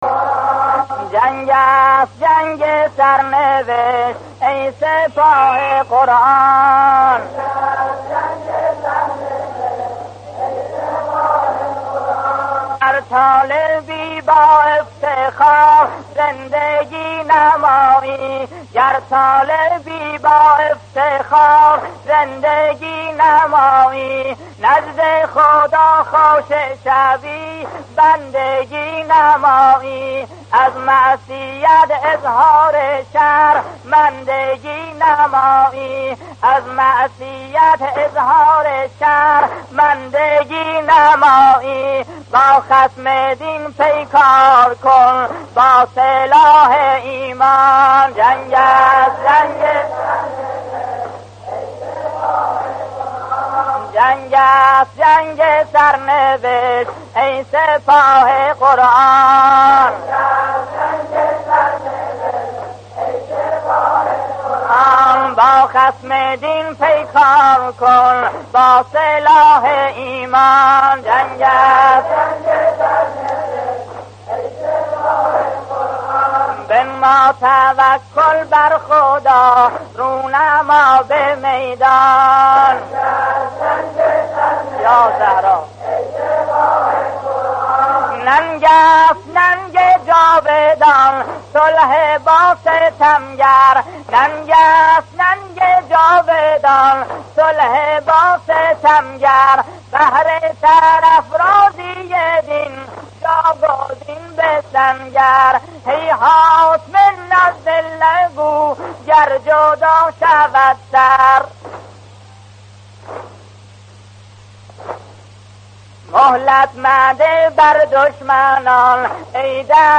مداحی های دفاع مقدس